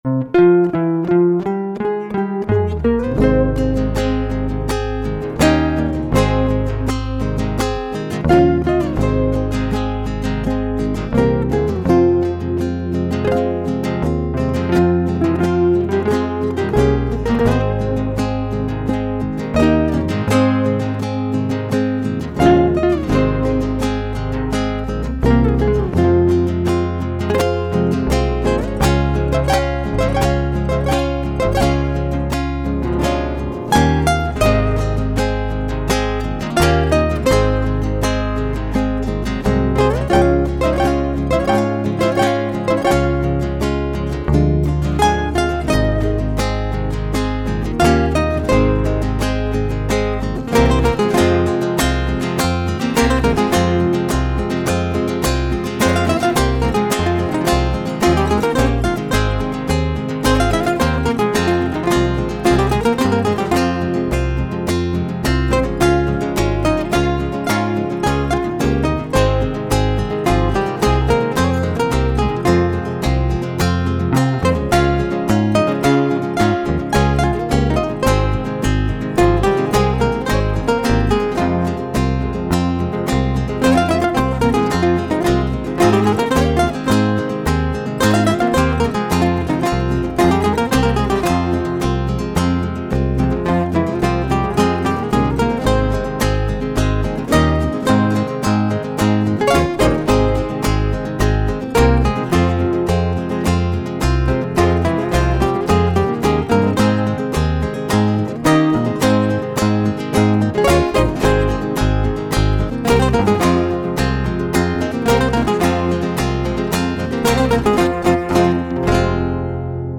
Folclore Argentino